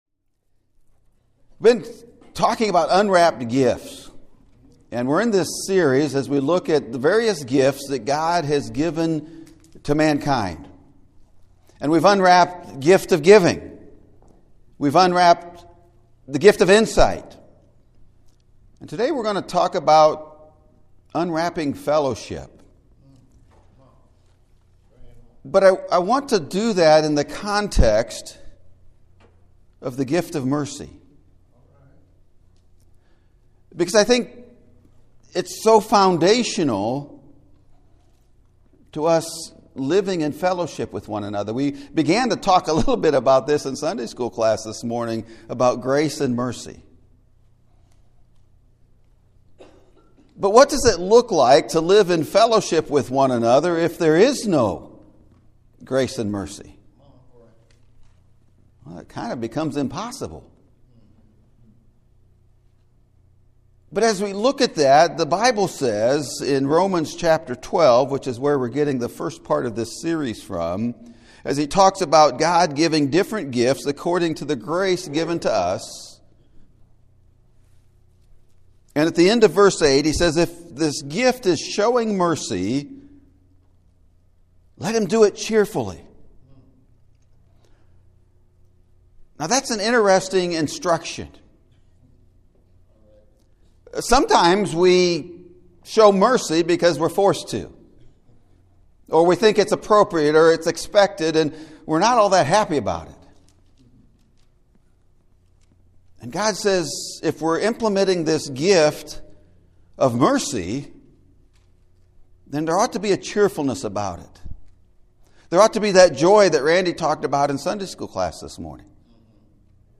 Unwrapped Fellowship: The Gift of MERCY! (Sermon Audio)